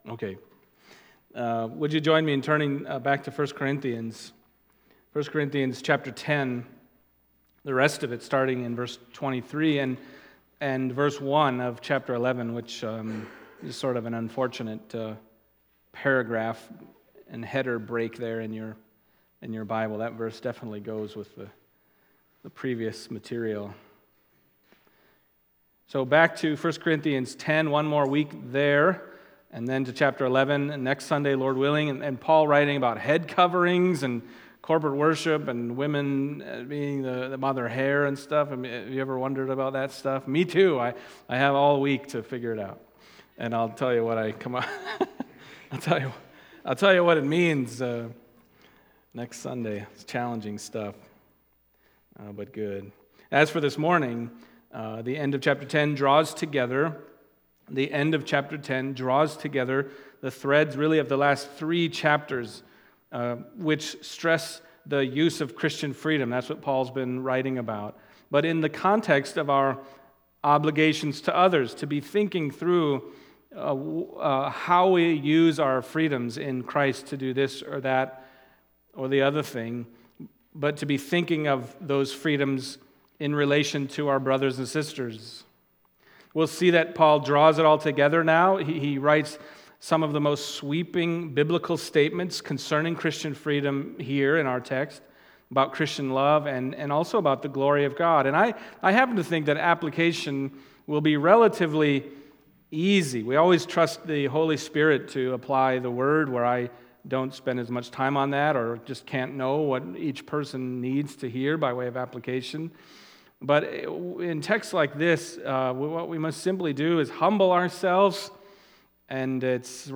Passage: 1 Corinthians 10:23-11:1 Service Type: Sunday Morning